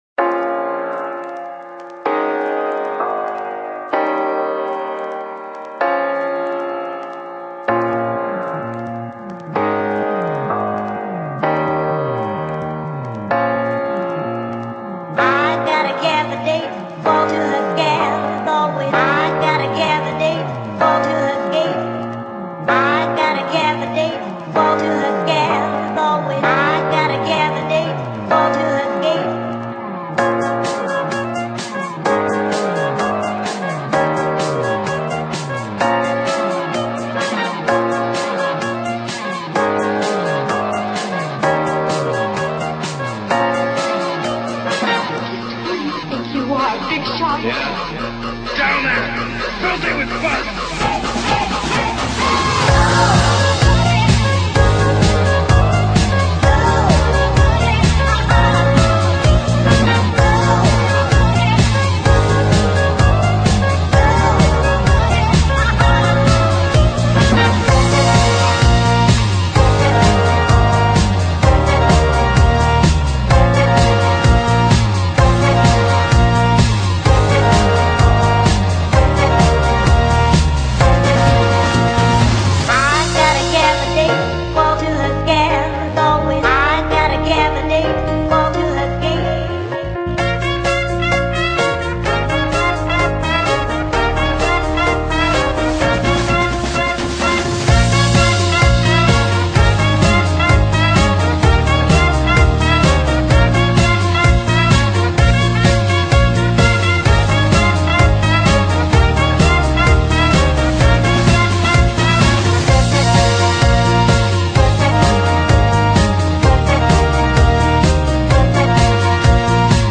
Jump-Jazz